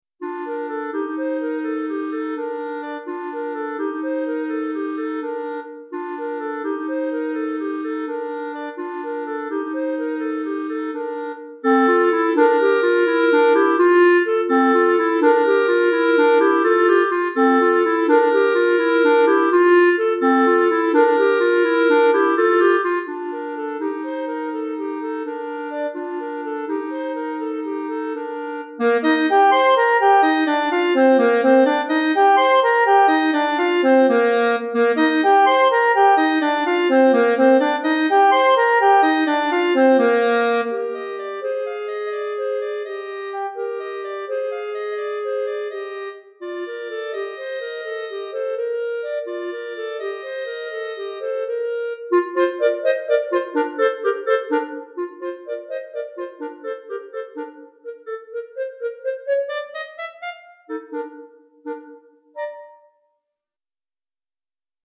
Répertoire pour Clarinette - 2 Clarinettes